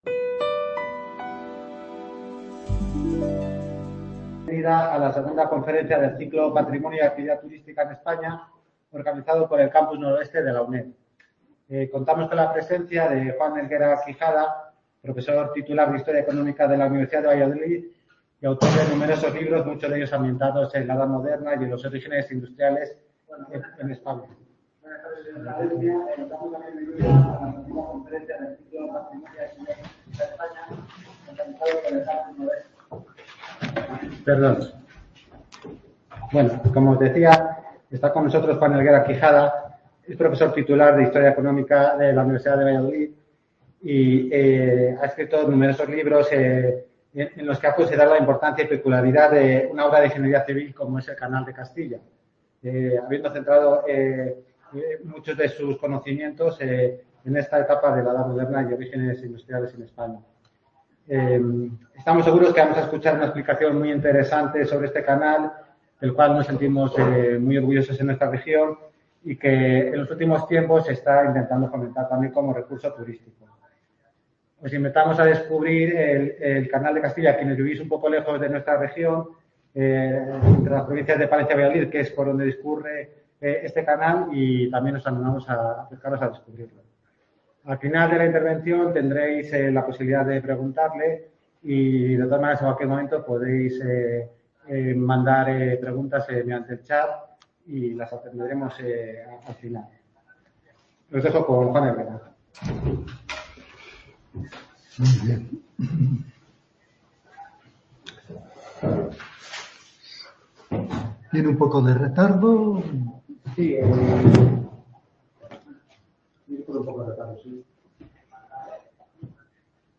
desde el Centro de la UNED en Palencia
conferencias